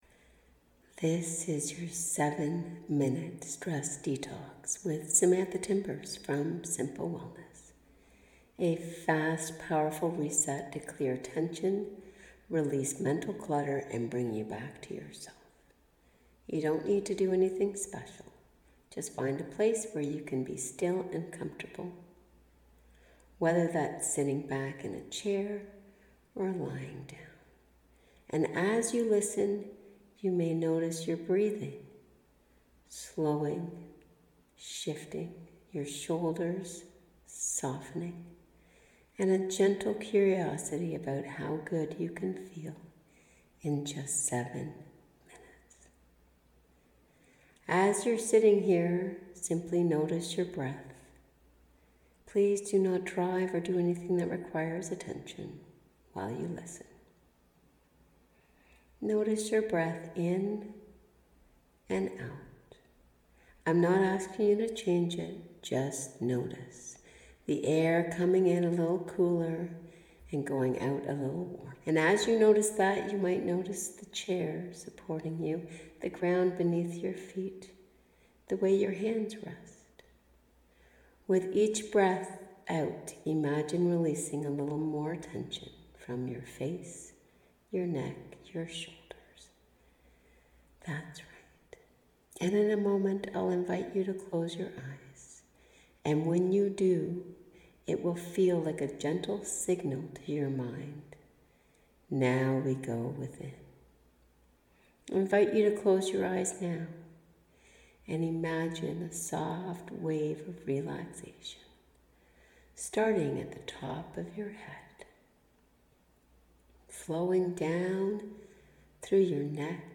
7 minute stress detox guided visualization.m4a